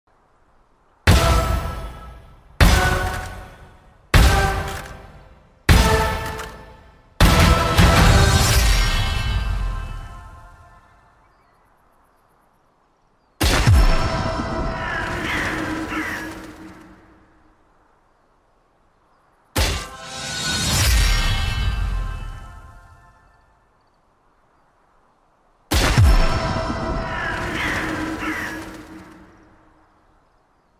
Sovereign Ace Sound & Finisher sound effects free download